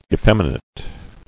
[ef·fem·i·nate]